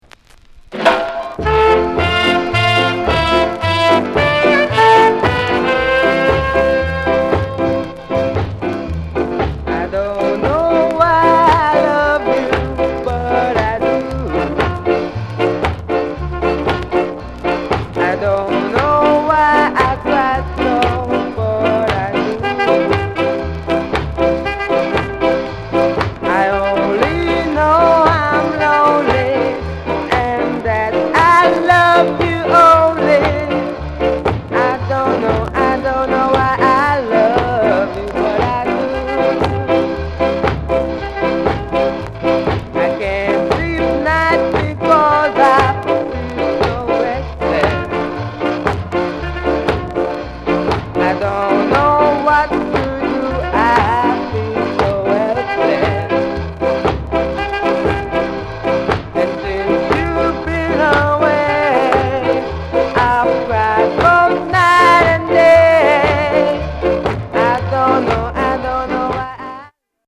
NICE SKA